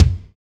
BD BD3F.wav